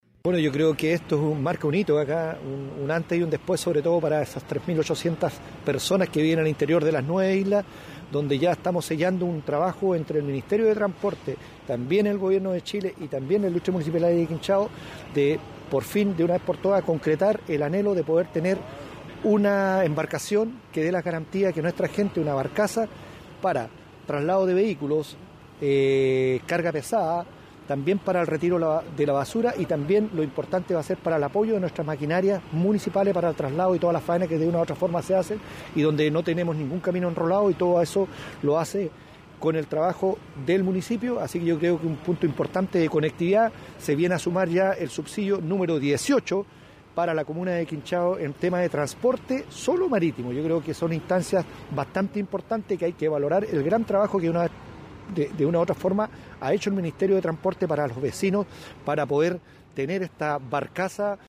El alcalde de la comuna de Quinchao, Washington Ulloa, dijo que esta modalidad de transbordo también favorecerá a los propios vehículos municipales, permitiendo llegar a las islas incluso con maquinaria para el arreglo de los caminos.
19-ALCALDE-QUINCHAO.mp3